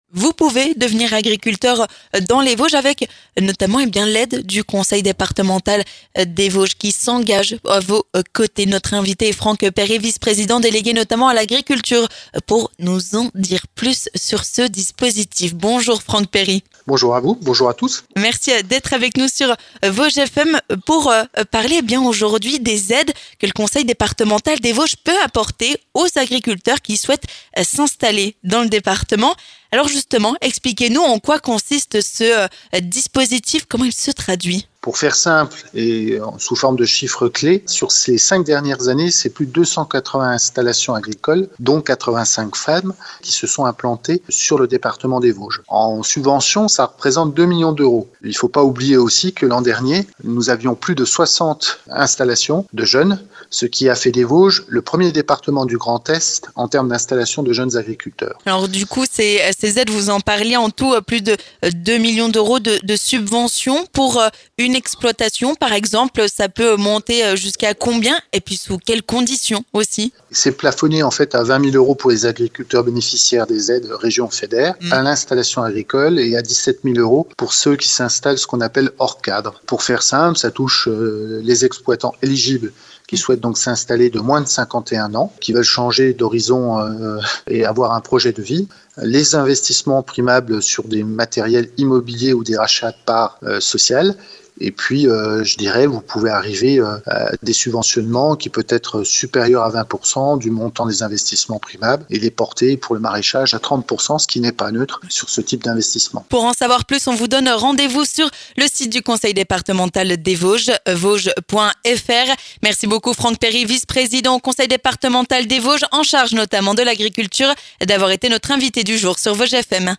L'invité du jour
On fait un point complet avec notre invité du jour, Franck Perry, vice-président du Conseil départemental des Vosges notamment délégué à l'agriculture.